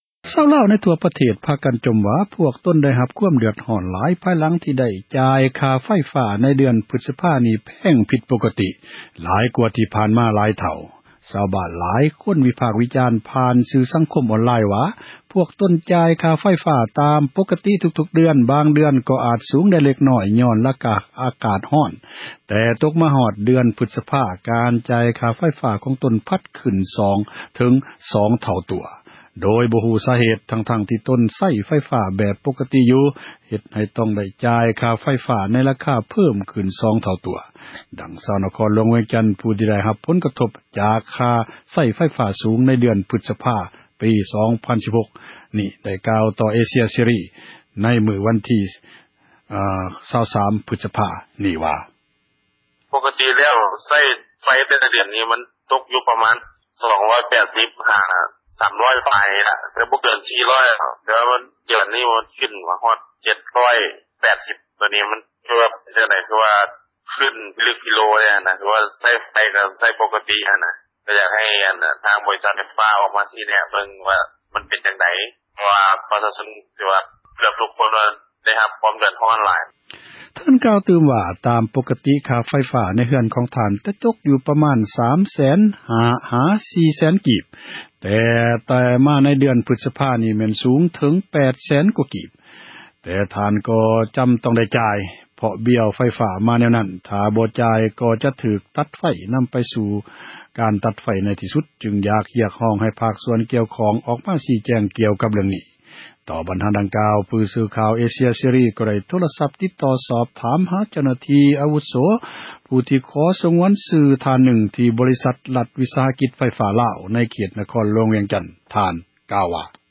ຊາວລາວ ເກືອບທົ່ວປະເທດ ພາກັນຈົ່ມວ່າ ພວກຕົນ ເດືອດຮ້ອນຫຼາຍ ທີ່ຕ້ອງໄດ້ຈ່າຍ ຄ່າໄຟຟ້າ ຂອງເດືອນພຶສພາ ແພງຜິດ ປົກກະຕິ ກວ່າ ທີ່ຜ່ານມາ ຫຼາຍເທົ່າ. ຊາວລາວ ວິພາກ ວິຈານ ຜ່ານ ສື່ ສັງຄົມ ອອນລາຍ ວ່າ ພວກຕົນ ຈ່າຍຄ່າໄຟຟ້າ ຕາມ ປົກກະຕິ ທຸກໆເດືອນ ບາງເດືອນ ກໍອາຈສູງແດ່ ເລັກນ້ອຍ ຍ້ອນ ອາກາດຮ້ອນ ແຕ່ຕົກມາ ເດືອນ ພຶສພາ ຄ່າໄຟຟ້າ ພັດເພີ້ມຂຶ້ນ 1 ທົບ ຫຼື 2 ທົບ ໂດຍບໍ່ຮູ້ ສາເຫຕ, ທັ້ງໆທີ່ໃຊ້ ໄຟຟ້າ ແບບ ປົກກະຕິ ຢູ່ແລ້ວ. ດັ່ງ ຊາວນະຄອນຫຼວງ ທ່ານນຶ່ງ ກ່າວຕໍ່ ວິທຍຸ ເອເຊັຽ ເສຣີ ໃນວັນທີ 23 ພຶສພາ 2016 ນີ້ວ່າ:
ຕໍ່ບັນຫາ ດັ່ງກ່າວນີ້, ຜູ້ສື່ຂ່າວ ເອເຊັຽເສຣີ ໄດ້ ໂທຣະສັບ ຕິດຕໍ່ຫາ ເຈົ້າໜ້າທີ່ ຂອງບໍຣິສັດ ຣັຖວິສຫະກິຈ ໄຟຟ້າລາວ ທີ່ ຂໍສງວນຊື່ ທ່ານນຶ່ງ ເວົ້າວ່າ: